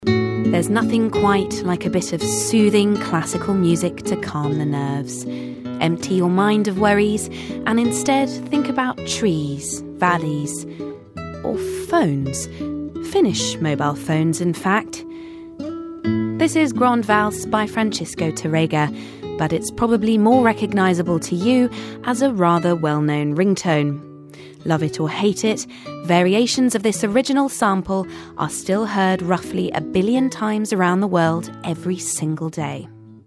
【英音模仿秀】小声音 大作用 听力文件下载—在线英语听力室